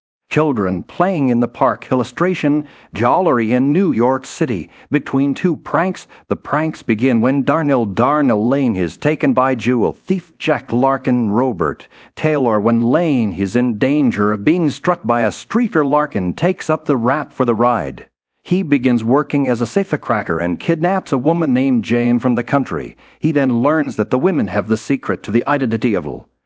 kids_playing_audio.wav